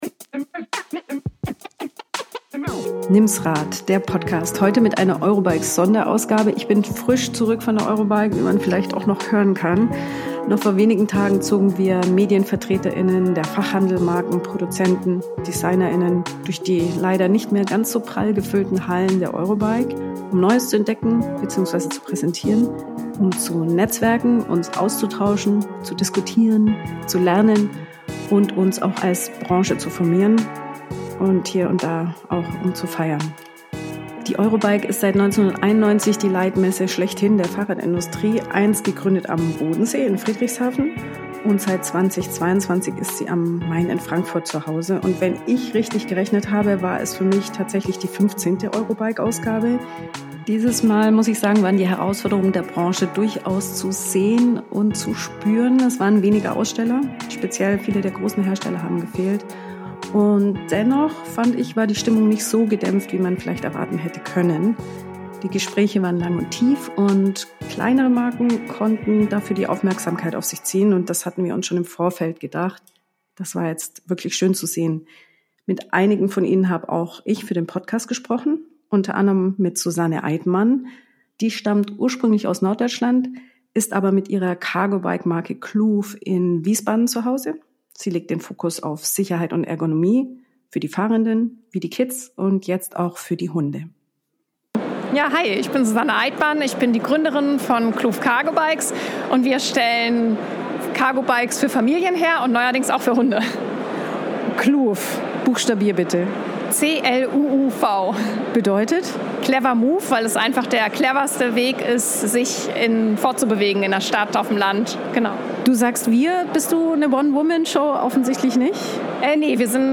Eurobike-Einblicke im Messe-Podcast: Stimmen zur Stimmung der Branche ~ Nimms Rad Podcast